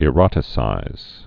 (ĭ-rŏtĭ-sīz)